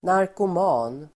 Ladda ner uttalet
narkoman substantiv, drug addict Uttal: [narkom'a:n]